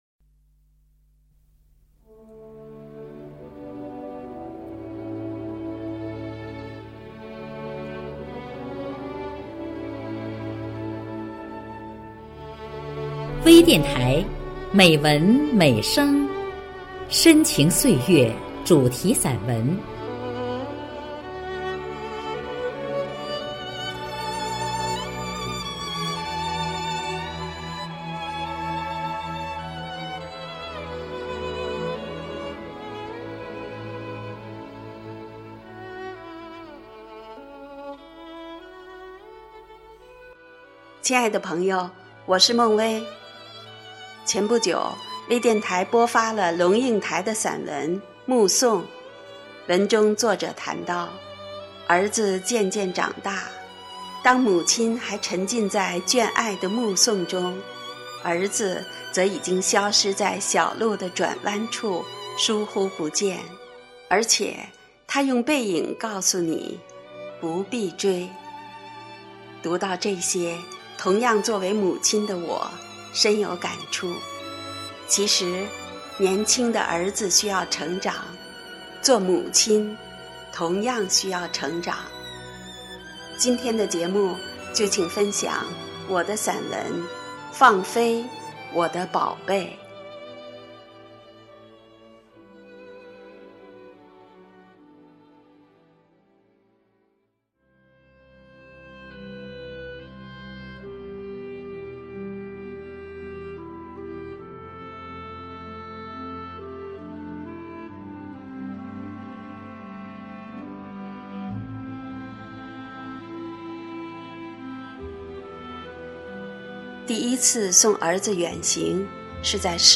《放飞我的宝贝》朗诵